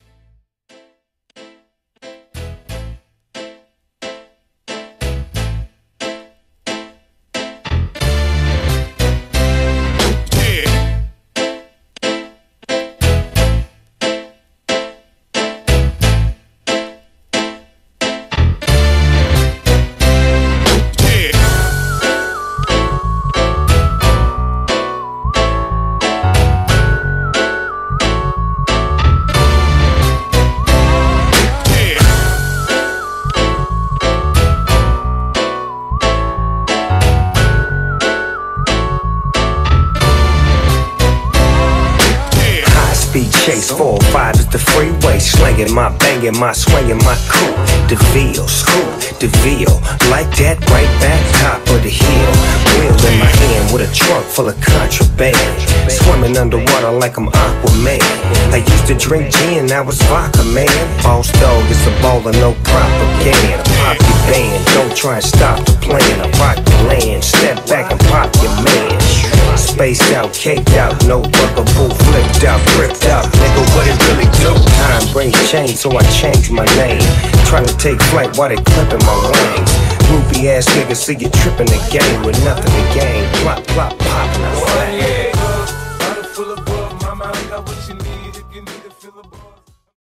HipHop Mashup